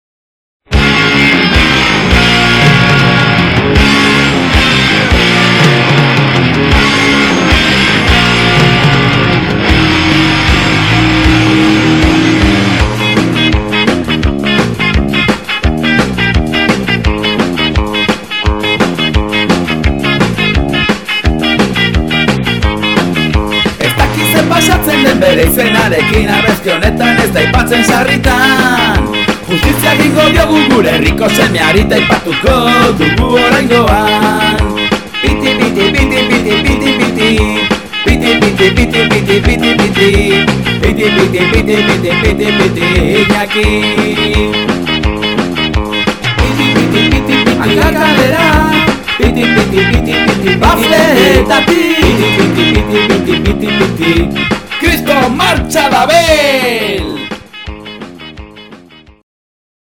abesti dantzagarriaren bertsioa